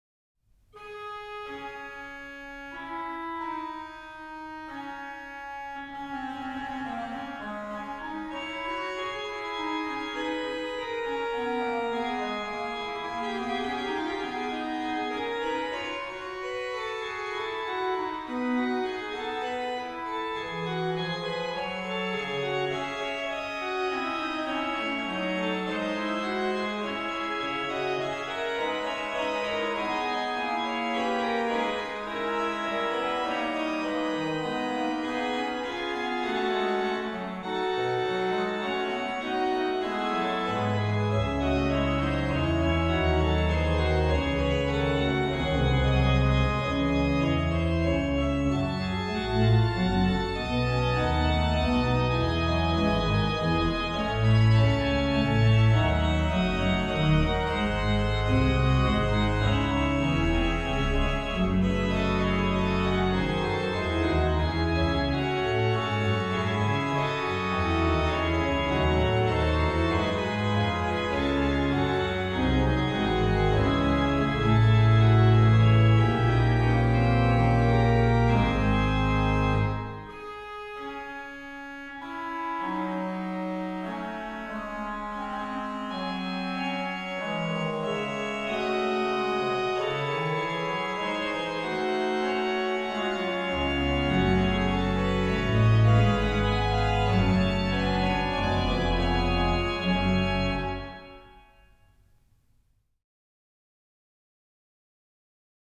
rh: HW: Por8, Rfl4, Oct2, Sesq
lh: BW: Ged8, Nacht8, Haut8
Ped: Sub16, Oct8, Oct4
2. Fuga (fragment)